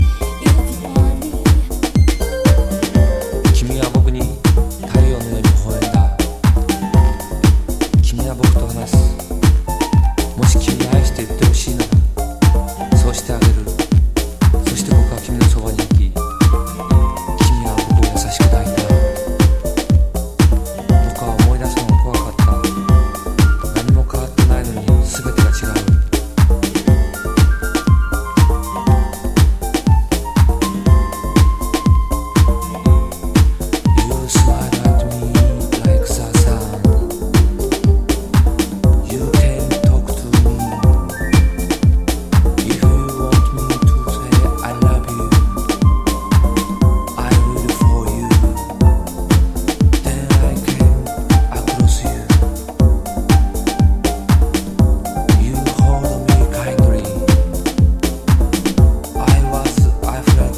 ベルギー産、日本語スポークン・アシッドスムース・ディープハウス